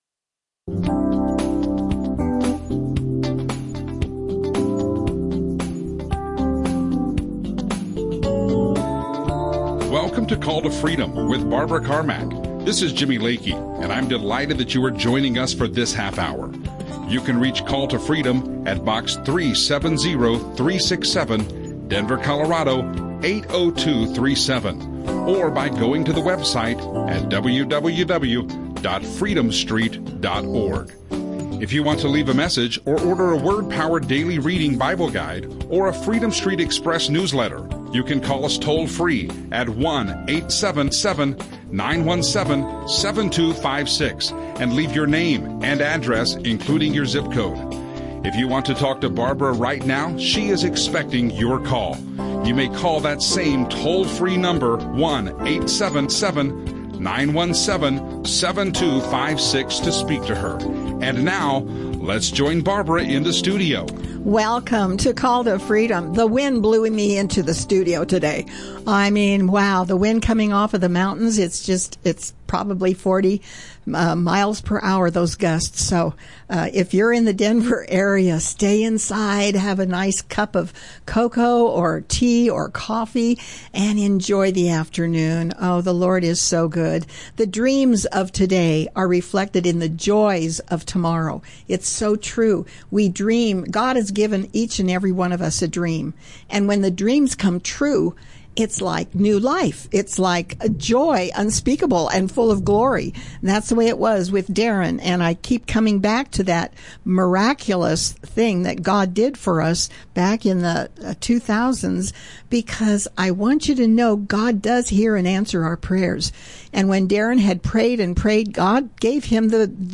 Christian radio